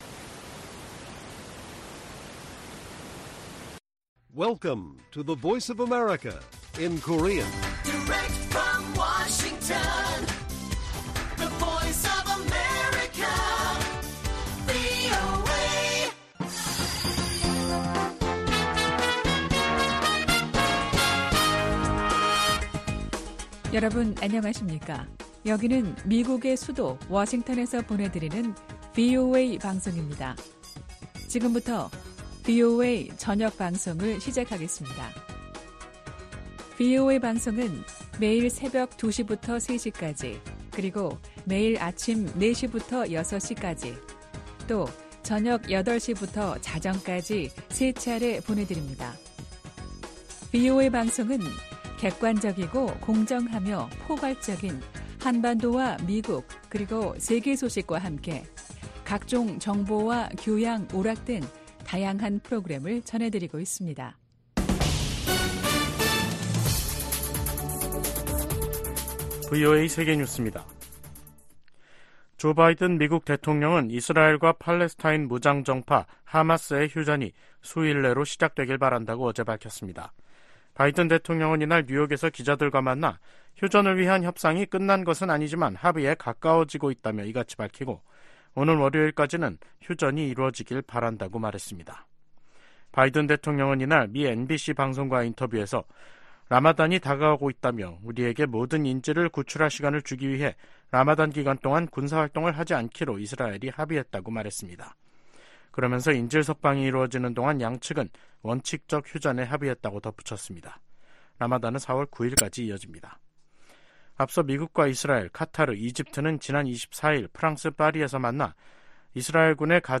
VOA 한국어 간판 뉴스 프로그램 '뉴스 투데이', 2024년 2월 27일 1부 방송입니다. 제네바 유엔 군축회의 첫날 주요국들이 한목소리로 북한의 핵과 미사일 개발을 강력히 비판했습니다.